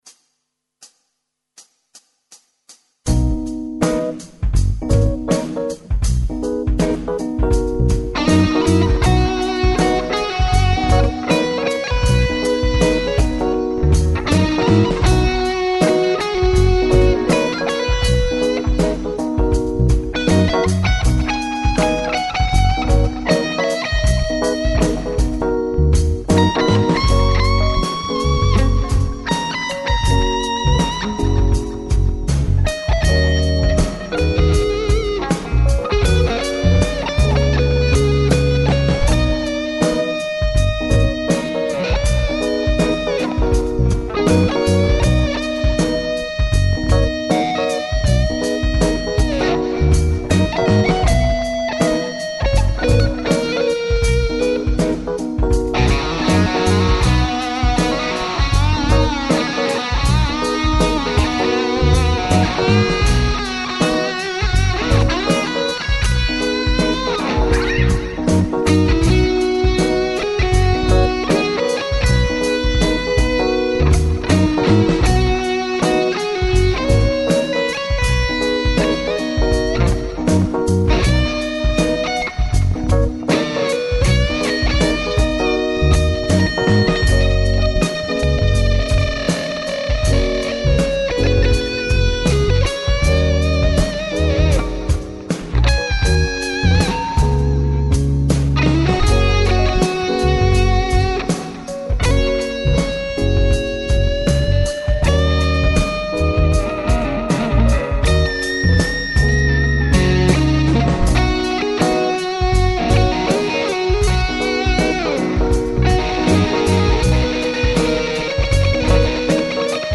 Sehr merkwürdige Akkorde ?
Ich selber kanns kaum auseinaderhalten - die GT kommt ziemlich gegen Ende noch dran.
Nachdem ja immer kritisieret wurde ich würde zu trocken aufnehmen - habe ich ordentlich Echo zugeschaltet.